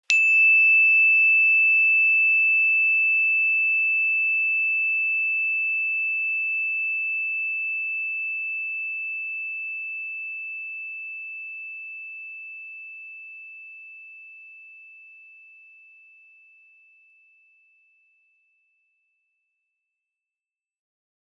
energychime_wood-E6-ff.wav